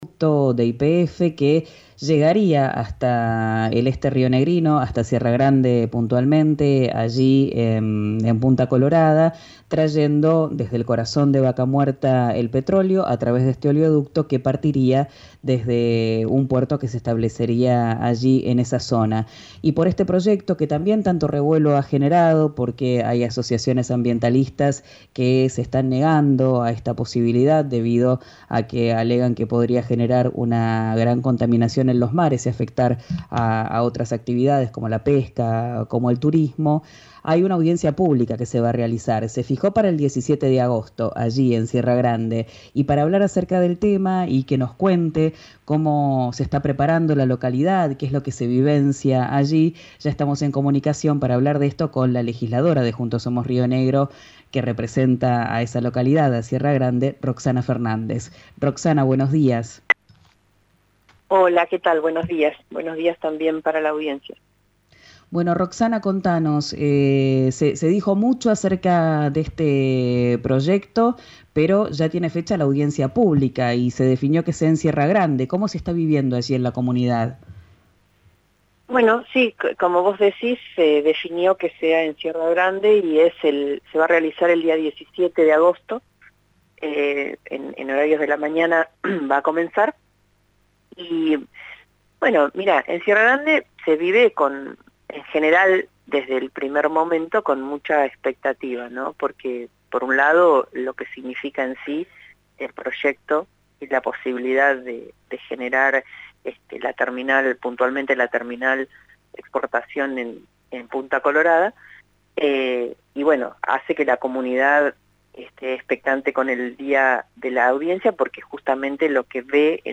Escuchá a la legisladora de Río Negro, Roxana Fernández, en RÍO NEGRO RADIO: